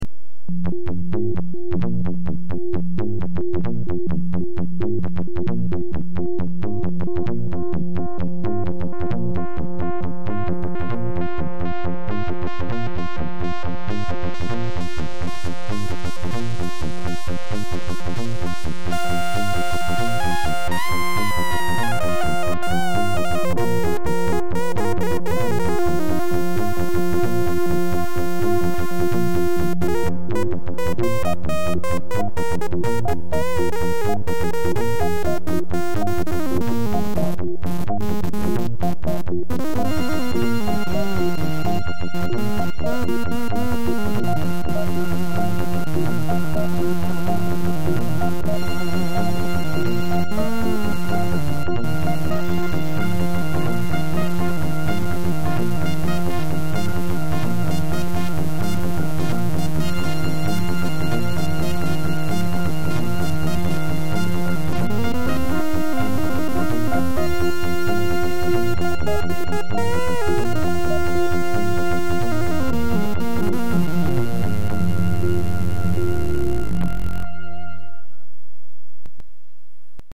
lab Yamaha DX 7
gq-Analog dx7.mp3